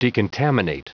Prononciation du mot decontaminate en anglais (fichier audio)
Prononciation du mot : decontaminate